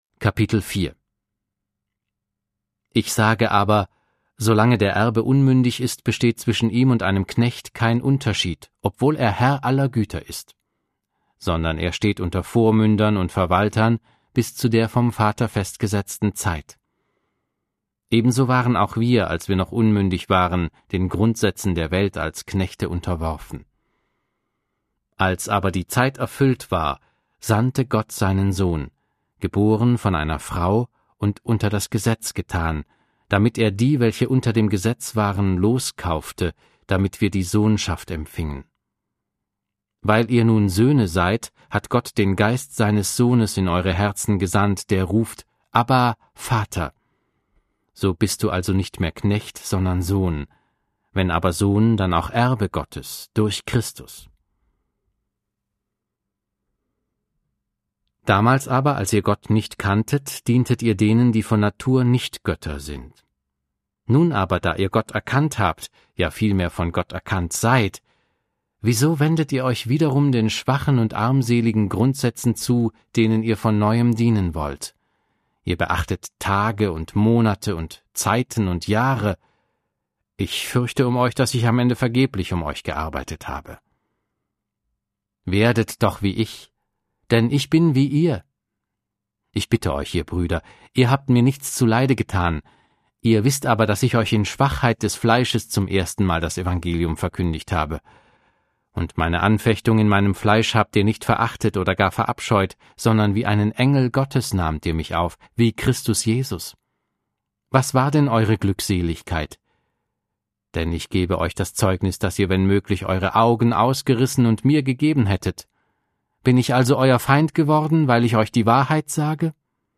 Audio Bibel - Schlachter 2000